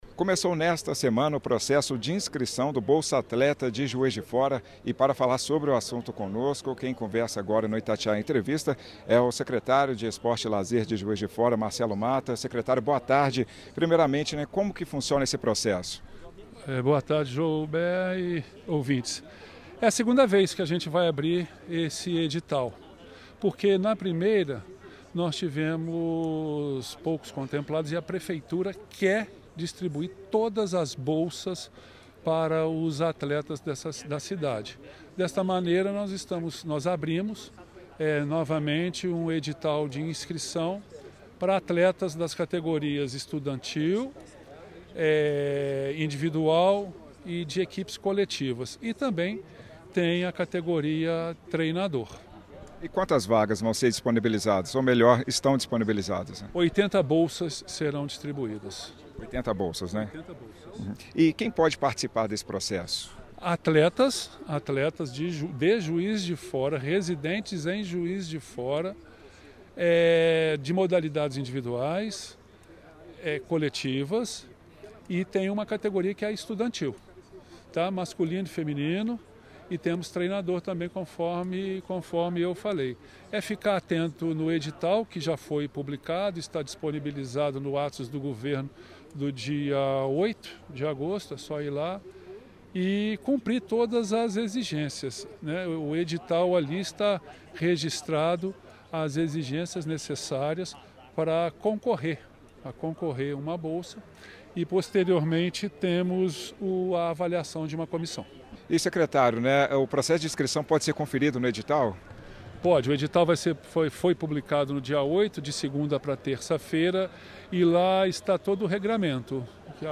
A Prefeitura de Juiz de Fora (PJF) publicou, nesta semana, um novo edital do programa Bolsa Atleta, que tem o objetivo realizar projetos esportivos, visando valorizar e beneficiar atletas e paratletas amadores representantes do município em competições regionais, estaduais, nacionais e internacionais, nas categorias individual, coletiva, técnica e estudantil. Para falar sobre o assunto, o Itatiaia Entrevista conversa com o secretário de Esporte e Lazer da PJF, Marcelo Matta.